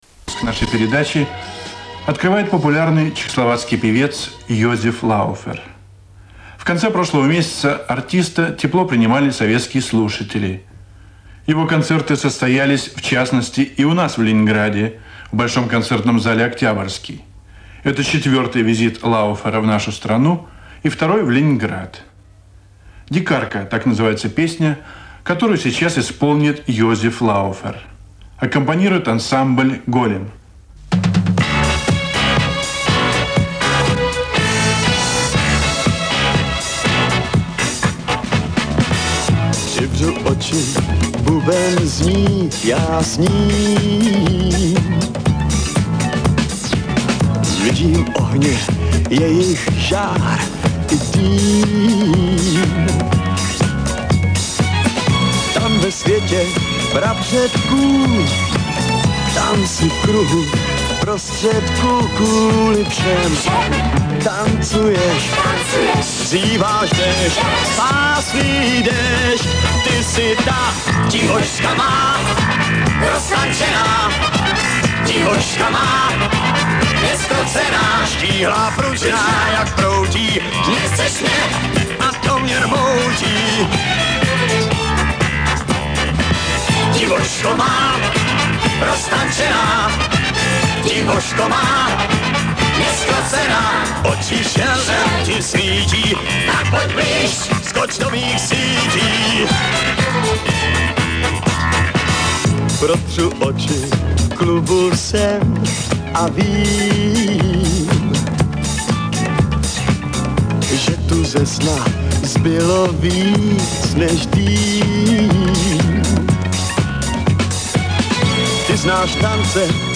Музыкальные радиопередачи